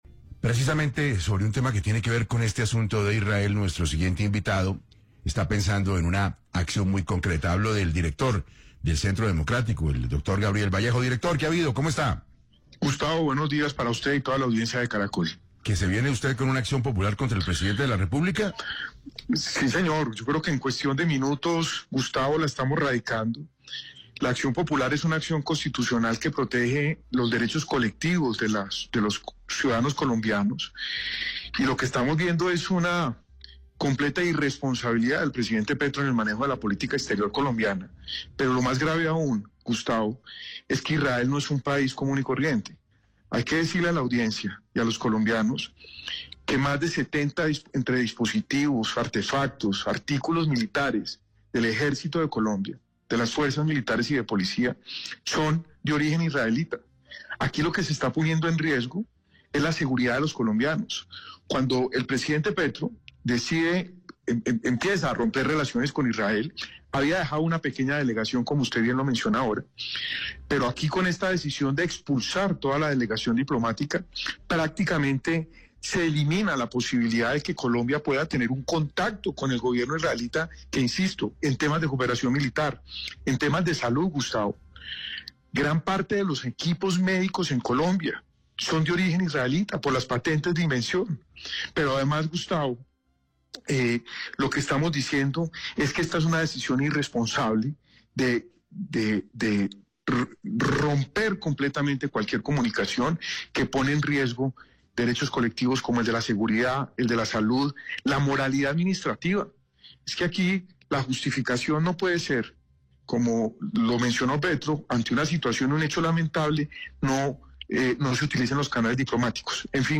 En entrevista con 6AM de Caracol Radio, el director del Centro Democrático, Gabriel Vallejo, lanzó fuertes críticas al presidente Gustavo Petro por su decisión de expulsar la delegación diplomática de Israel en Colombia.